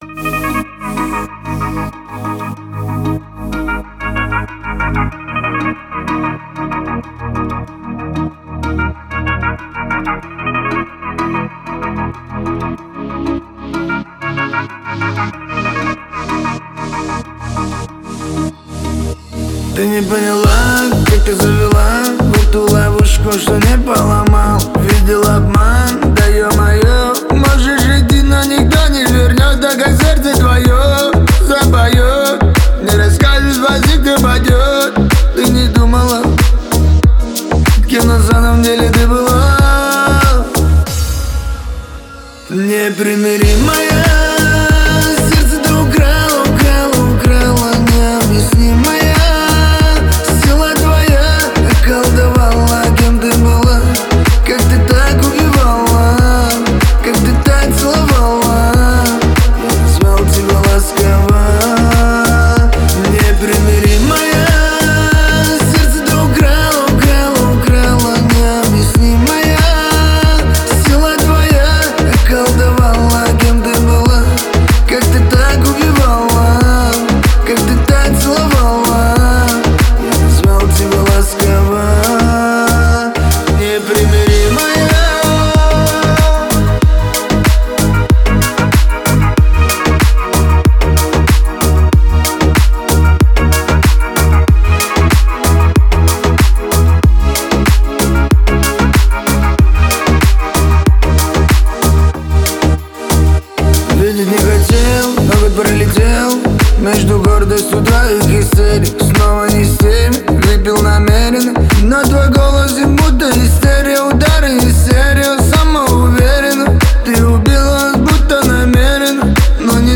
Танцевальная музыка
Песни на дискотеку
танцевальные песни